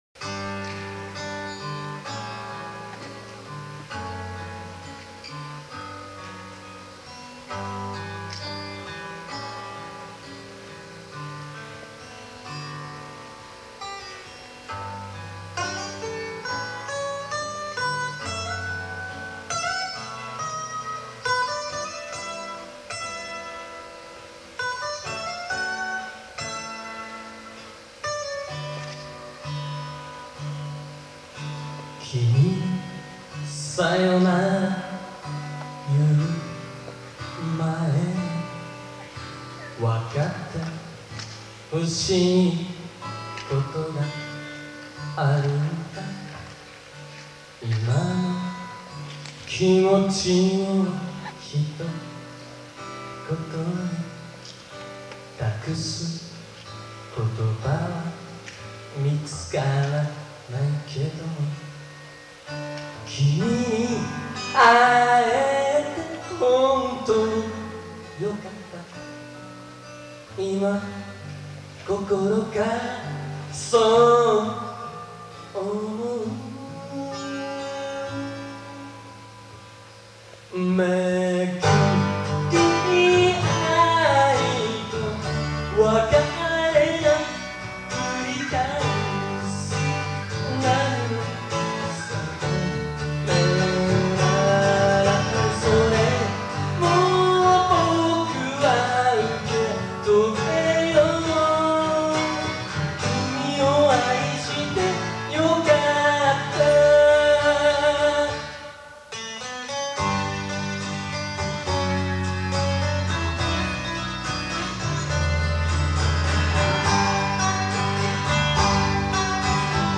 場所：鶴見会館